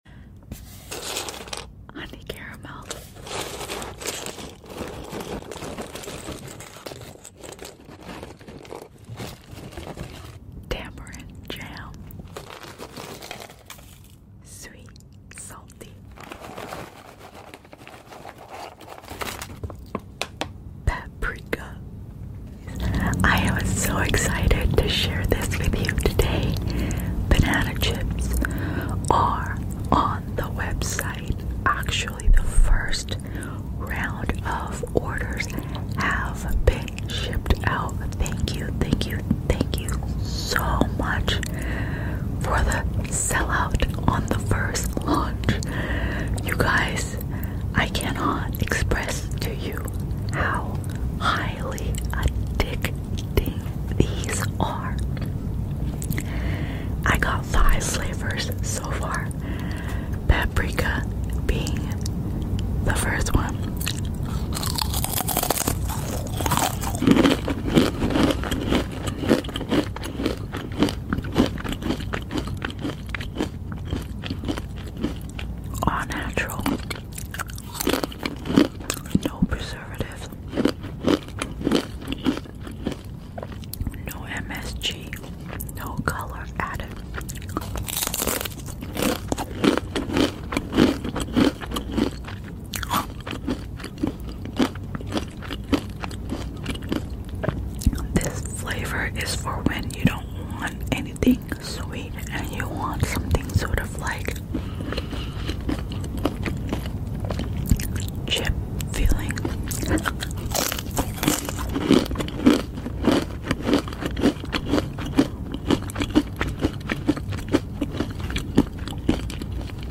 ASMR BANANA CHIPS _WARNING_ HIGHLY sound effects free download
ASMR BANANA CHIPS _WARNING_ HIGHLY ADDICTIVE (CRUNCHY EATING SOUNDS) LIGHT WHISPERS _ Ep1